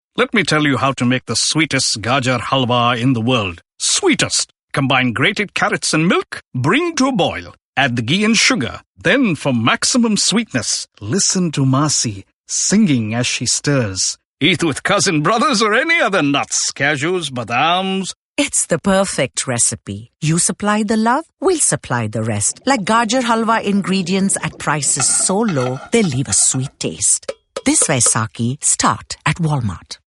SilverMulticultural - Radio
75937 – Vaisakhi – RADIO – Sweet